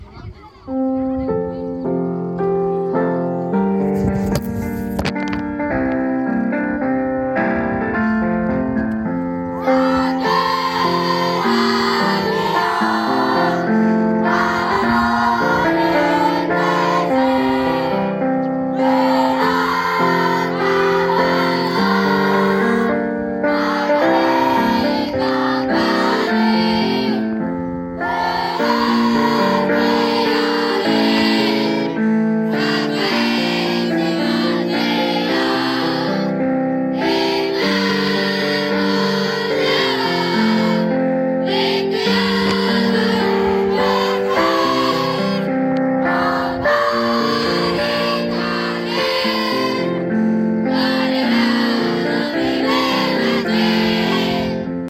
人数は少なくても「町民の歌」は町内どの学校よりも大きな声で歌おうと、毎日練習しました。
町民の歌児童合唱.mp3